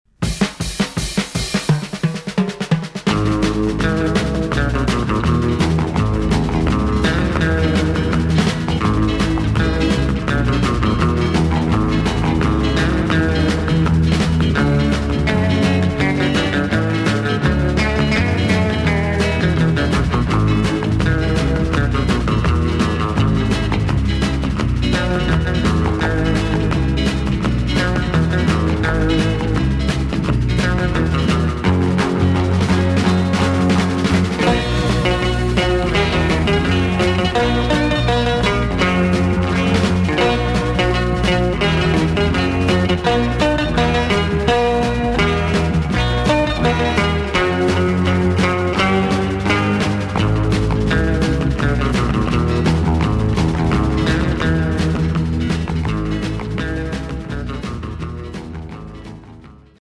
3.0 CHILEAN INSTRUMENTALS FROM 60's